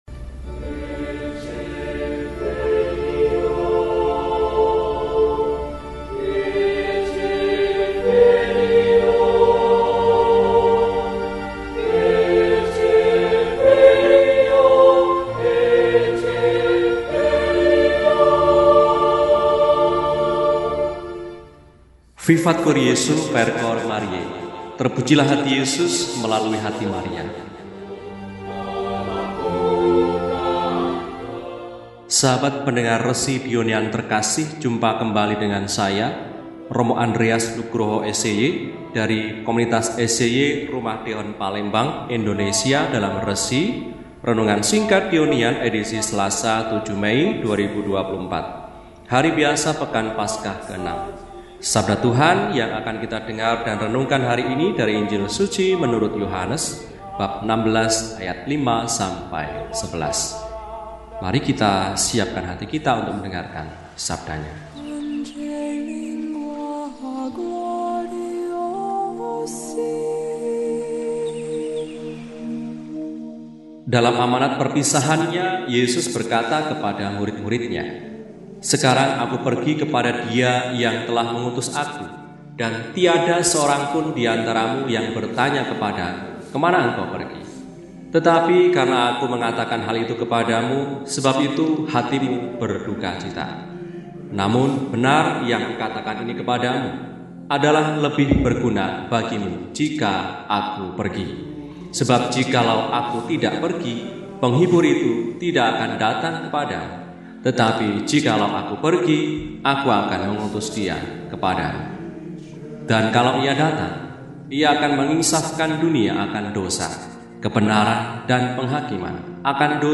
Selasa, 07 Mei 2024 – Hari Biasa Pekan VI Paskah – RESI (Renungan Singkat) DEHONIAN